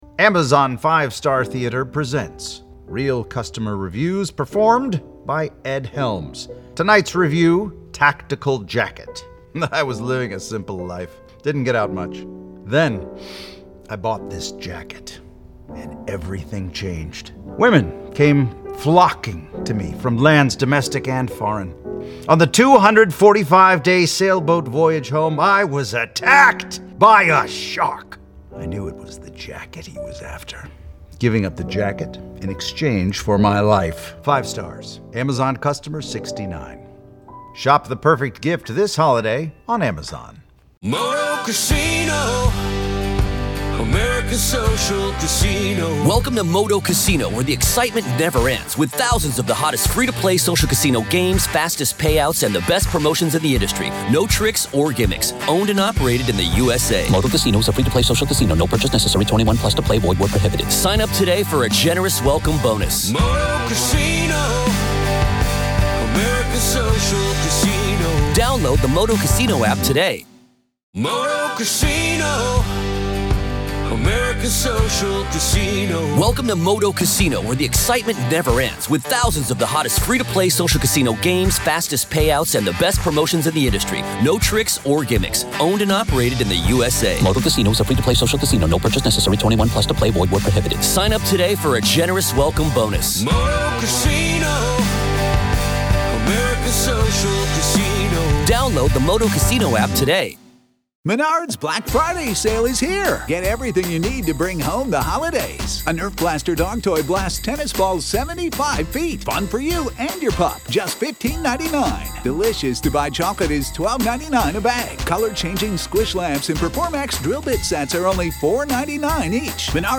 This is the complete essay, read verbatim—no edits, no commentary, no interruptions. Kohberger details how to secure a crime scene, avoid leaving DNA, catalog evidence, and analyze behavior at the scene of a fictional stabbing.